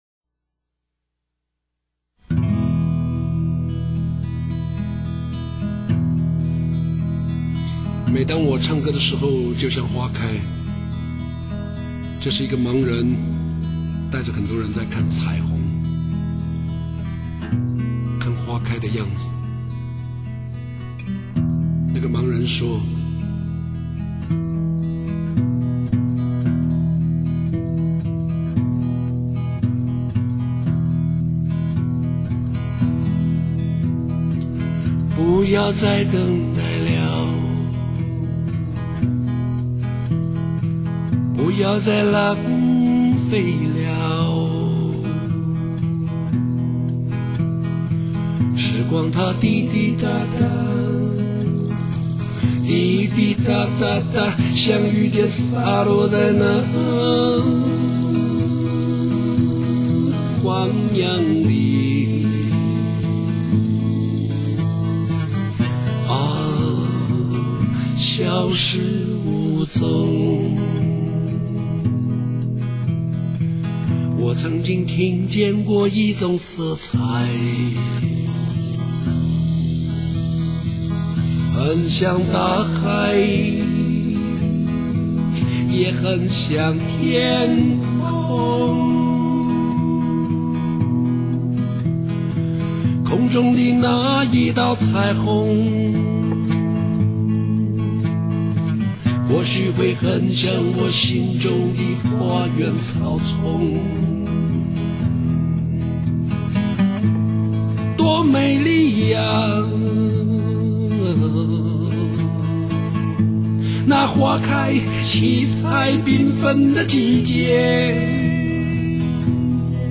新颖而独特的蒙古风情和大漠音乐
整体音乐简单又非常丰富，将马头琴、内蒙民谣、驼铃等特色元素完美结合
这是一张将艺术、文学、美学完美结合的艺术品，沧桑的灵魂歌声，富有哲理的美丽文字……把每一首歌都完整的听了一遍，非常好听。